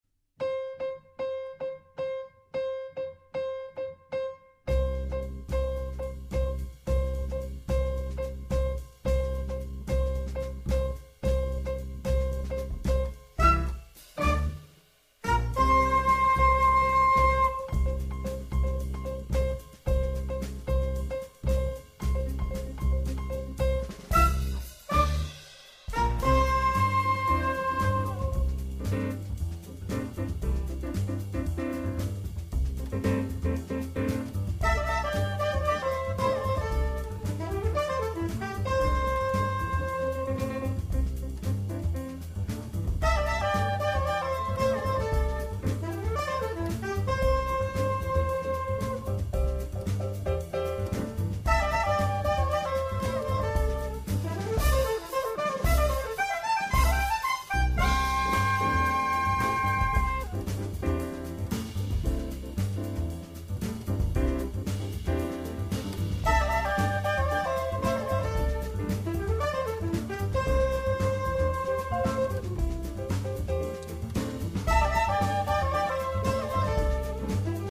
sax tenore e soprano
pianoforte
contrabbasso
batteria